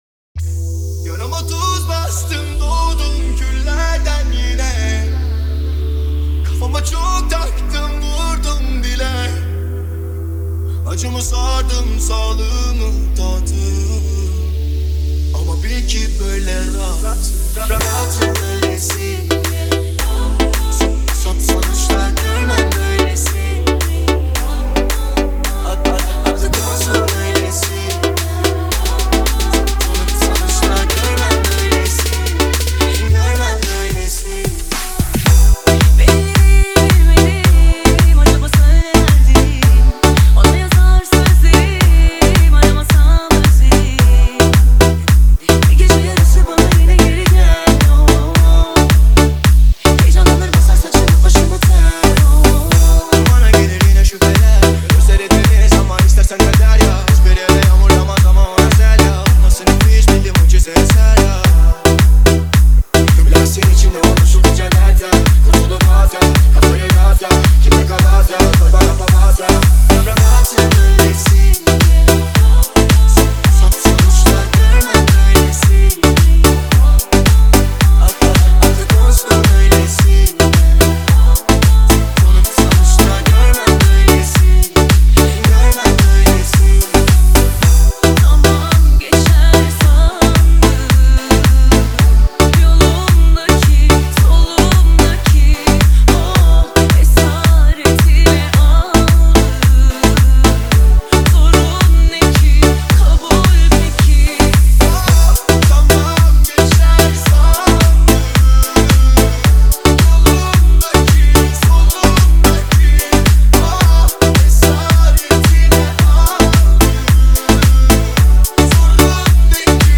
آهنگ ترکیه ای آهنگ شاد ترکیه ای آهنگ هیت ترکیه ای ریمیکس